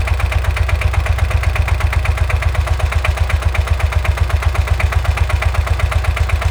EngineIdle.aif